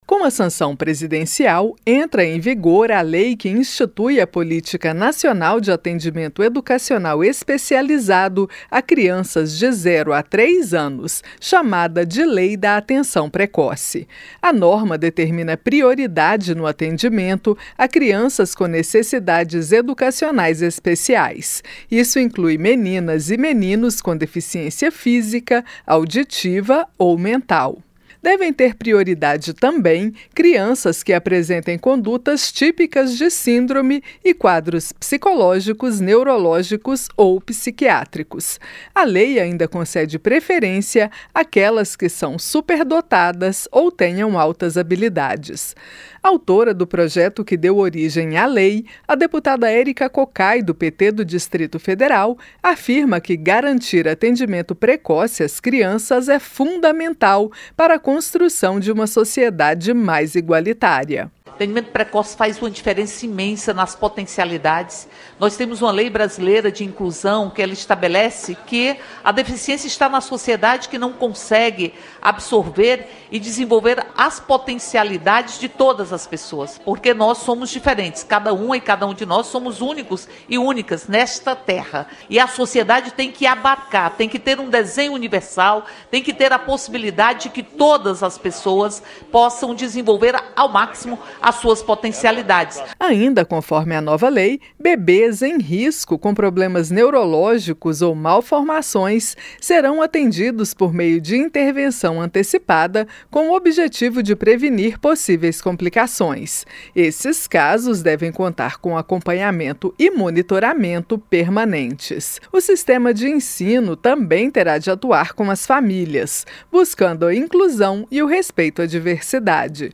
LEI PREVÊ ATENÇÃO ESPECIAL A CRIANÇAS COM ATÉ TRÊS ANOS QUE PRECISEM DE CUIDADOS ESPECÍFICOS. OS DETALHES COM A REPÓRTER